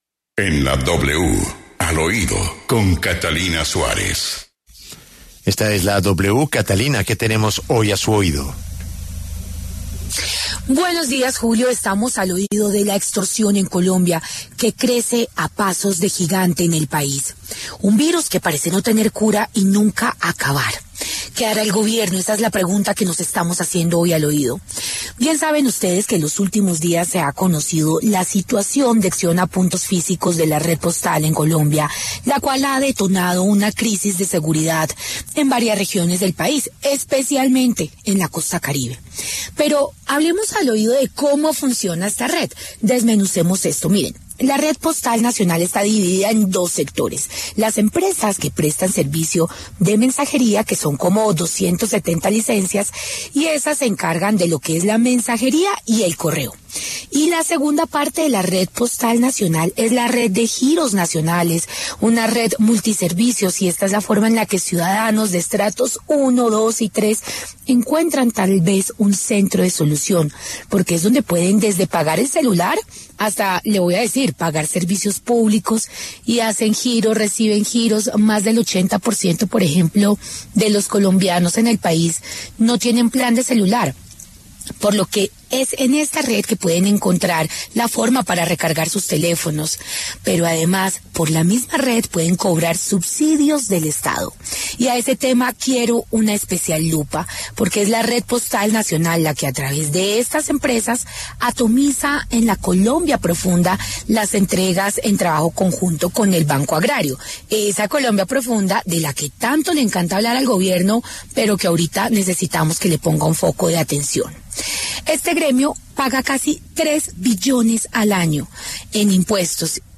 La W Radio